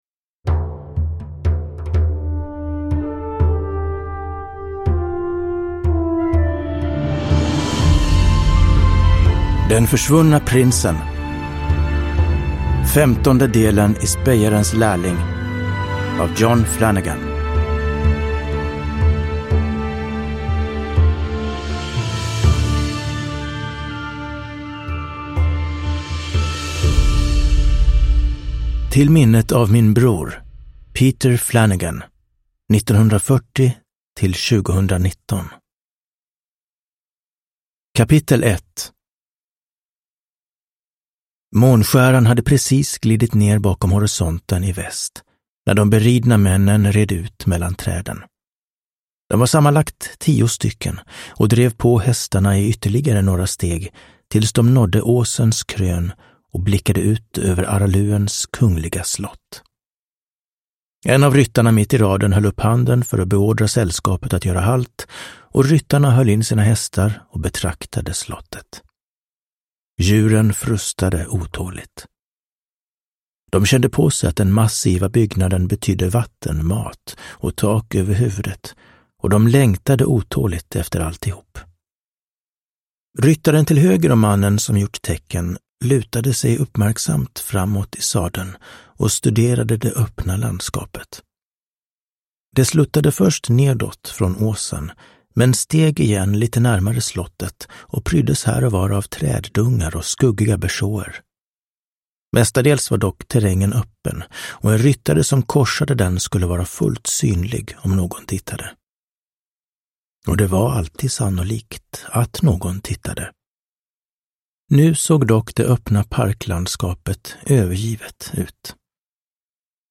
Den försvunna prinsen – Ljudbok – Laddas ner